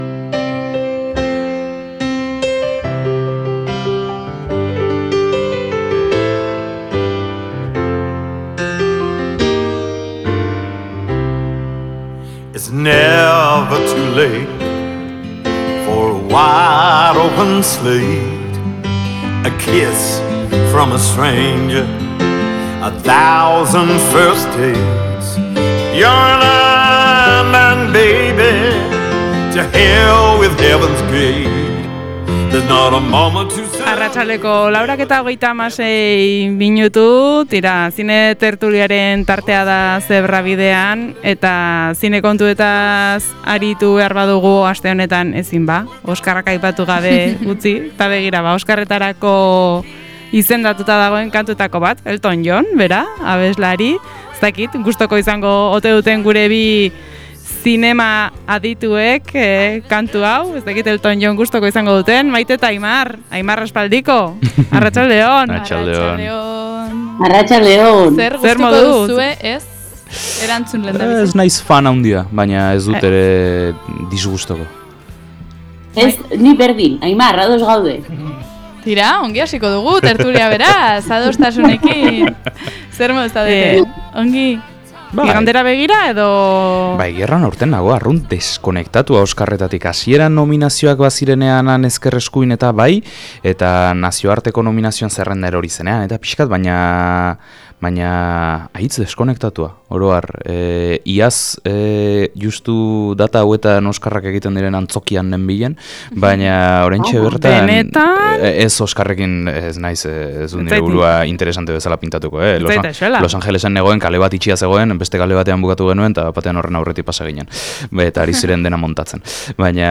Zinema tertulia · 02.26